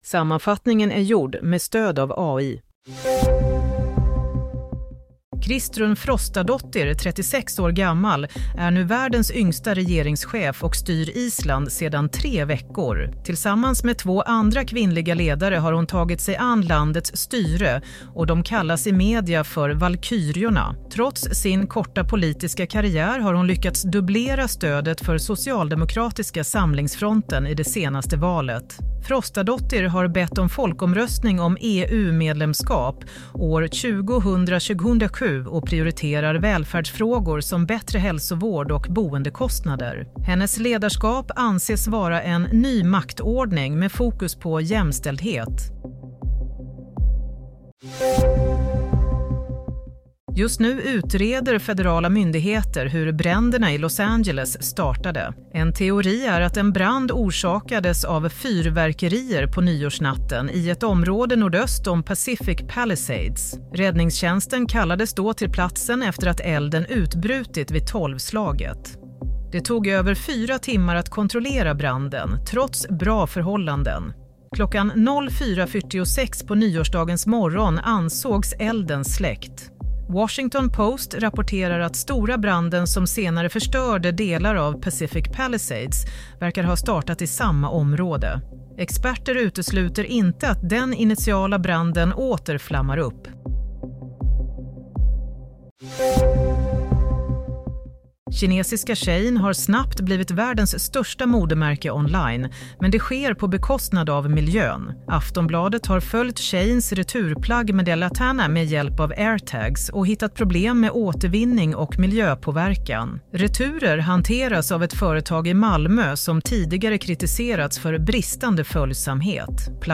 Play - Nyhetssammanfattning – 13 januari 07:00
Sammanfattningen av följande nyheter är gjord med stöd av AI.